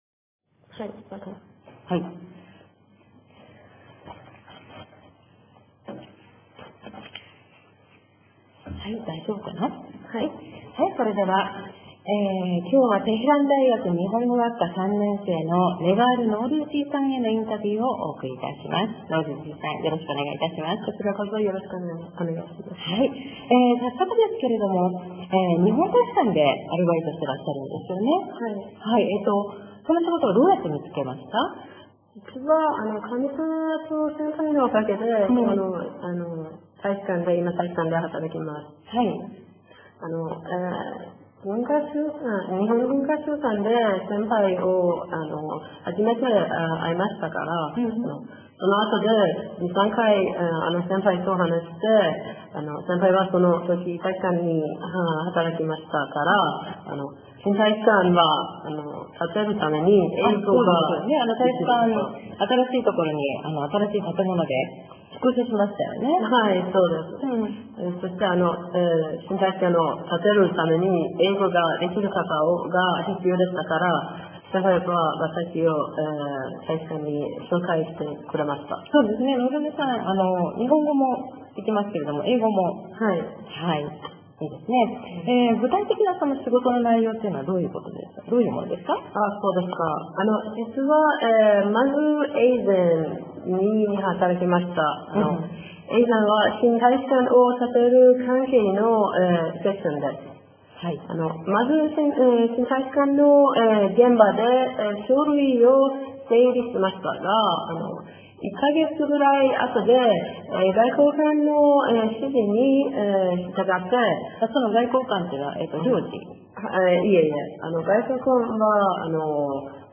テヘラン大学日本語学科3年生へのインタビュー